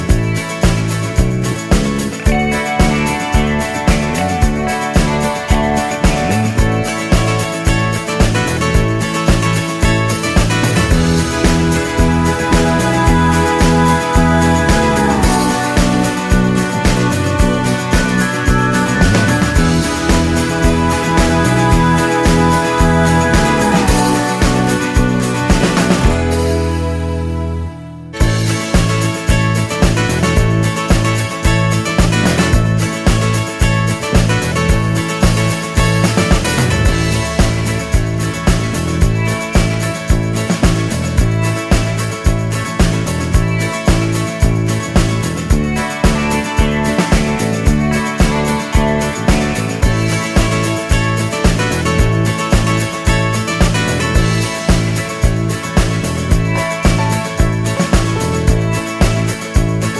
Twofers Medley Pop (2000s) 4:14 Buy £1.50